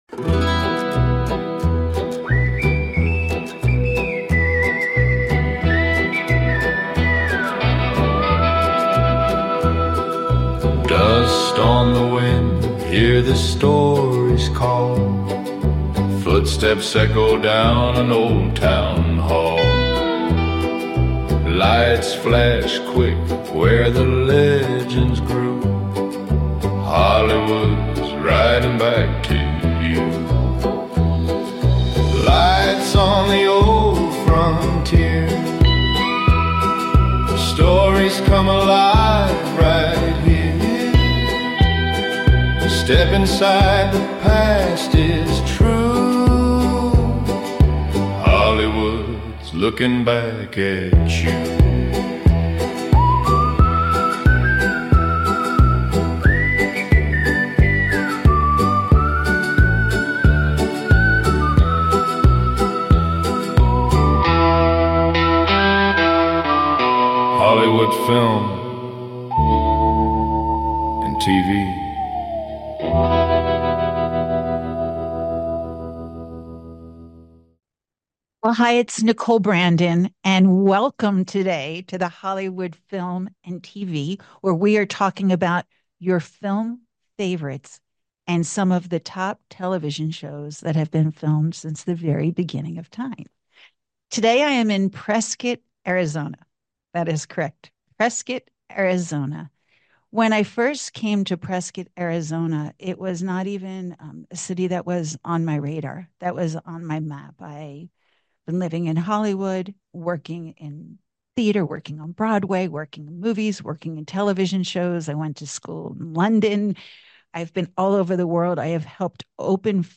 Talk Show Episode, Audio Podcast, Hollywood Film And TV Magic and The Hidden Heart of Cinema, Exploring Prescott Arizona’s Film Legacy on , show guests , about Heart of Cinema,Cinematic Discovery,Pioneering Social Change,Film Industry Standards,Next Generation of Filmmakers,Preserving the Past,Looking Forward,silent films,American entertainment industry, categorized as Arts,Performing Arts,Entertainment,Health & Lifestyle,Inspirational,Society and Culture,Travel & Leisure,Tourism,TV & Film